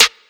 Waka SNARE ROLL PATTERN (45).wav